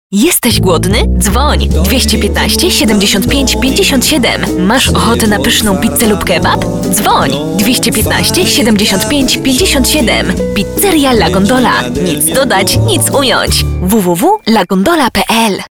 Sprecherin polnisch für TV / Rundfunk / Industrie.
Sprechprobe: Industrie (Muttersprache):
polish female voice over artist